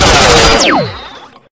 rifle2.ogg